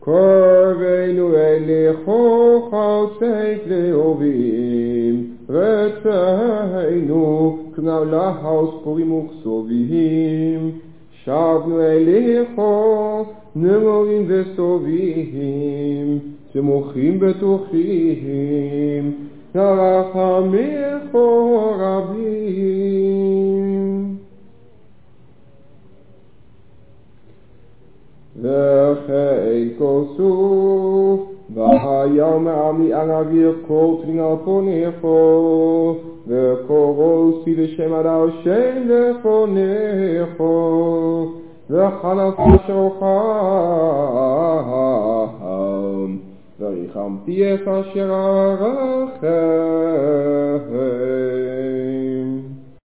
op cassettebandjes.